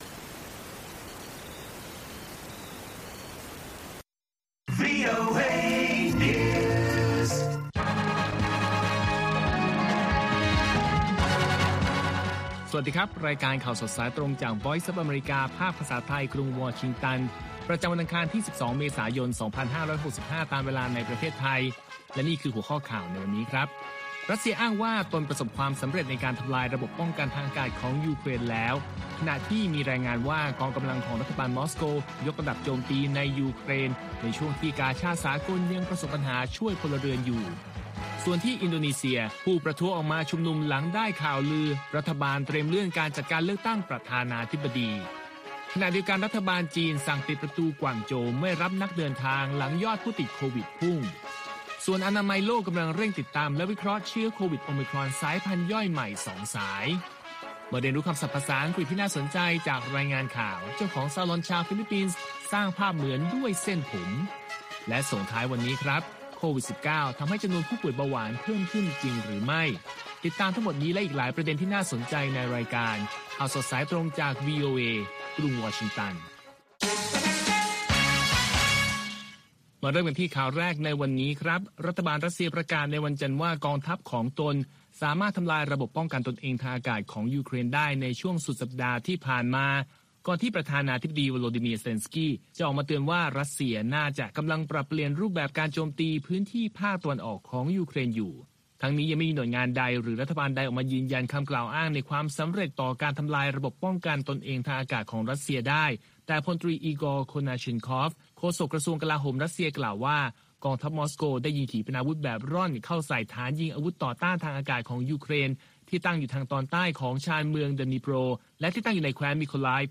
ข่าวสดสายตรงจากวีโอเอ ภาคภาษาไทย ประจำวันอังคารที่ 12 เมษายน 2565 ตามเวลาประเทศไทย